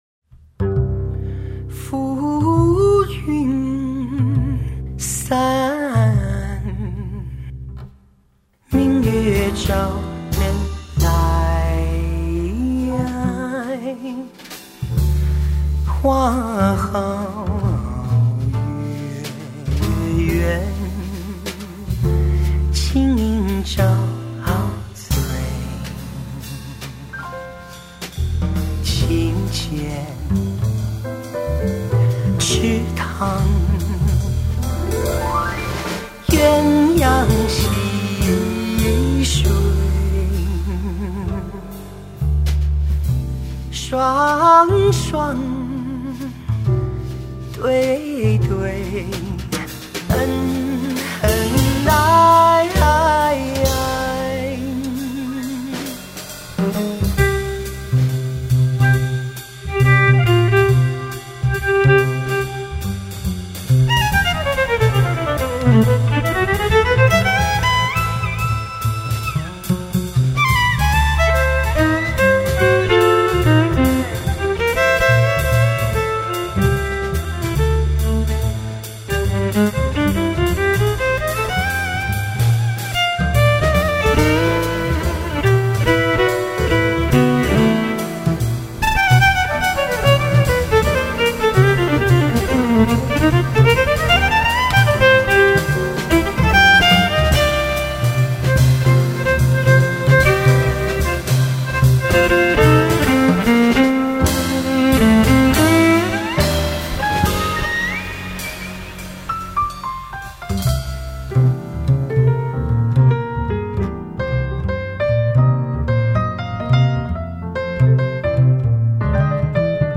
melifluous vocalese
piano
violin